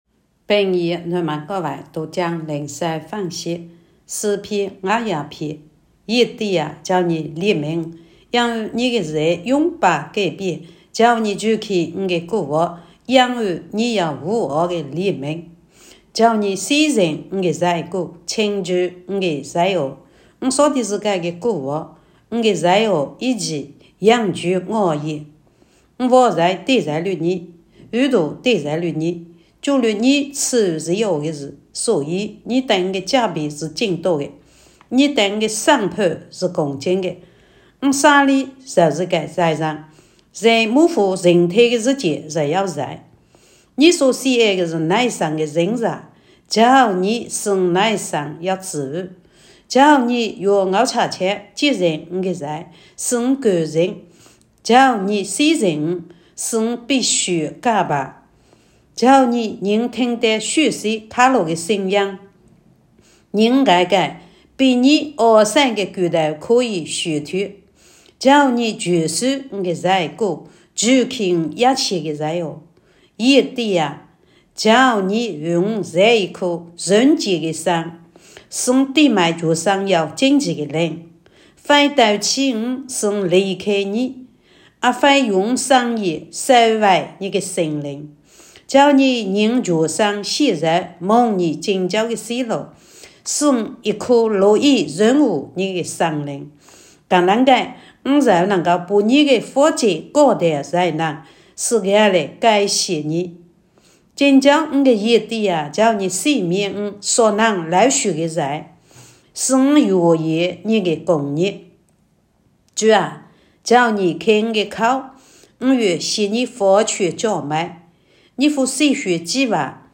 平阳话朗读——诗51